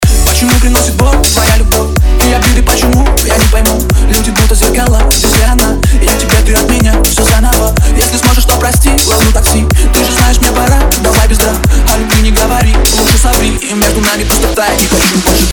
Стерео расширение баса
Прикрепил отрывок из референса. Очень понравилось, как оформлен бас по стерео.